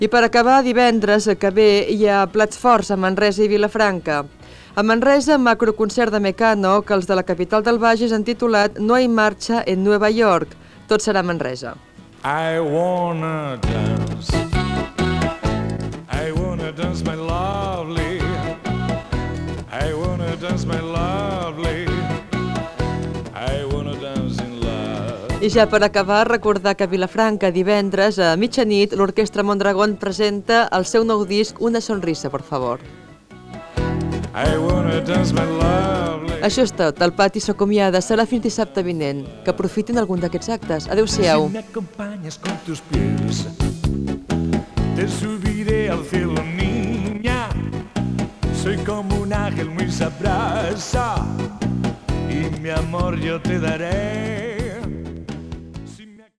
Concerts a Manresa i Vilafranca i comiat del programa Gènere radiofònic Entreteniment